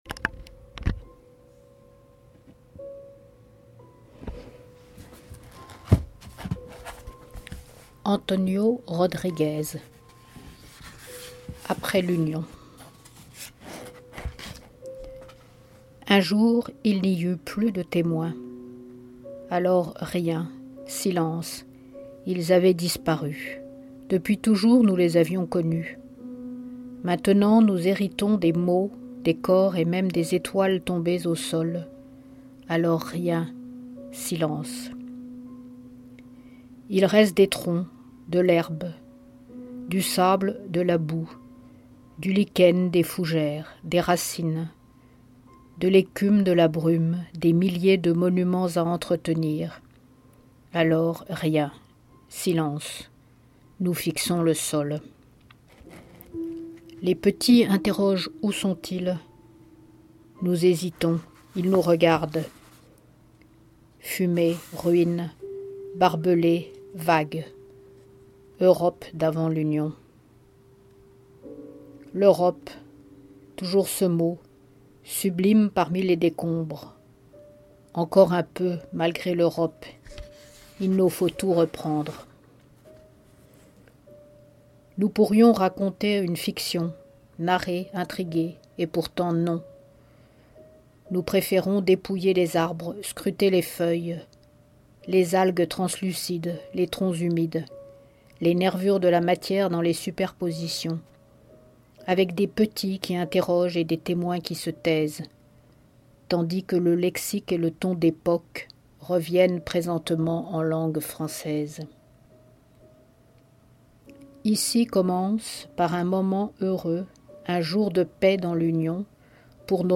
MicroLectures d'extraits de quatre des livres reçus cette semaine par Poezibao.